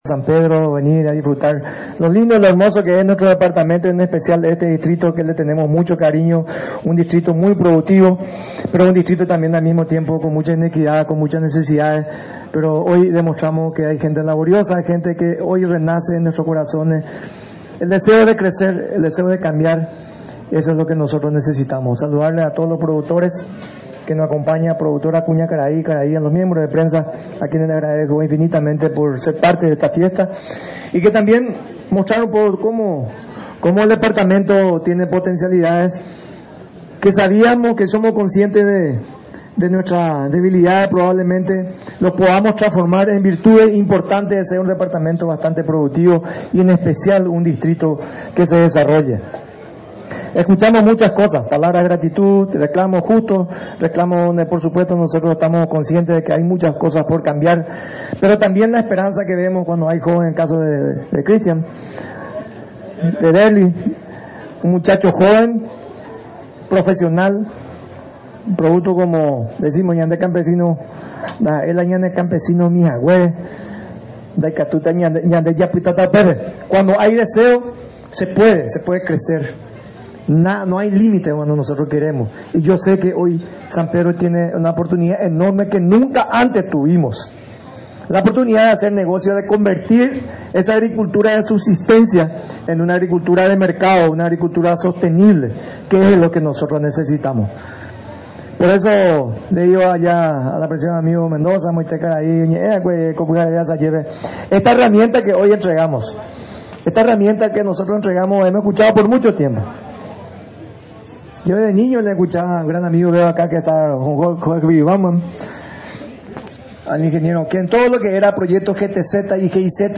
AUDIO: DR. CARLOS GIMÉNEZ-MINISTRO DEL MAG.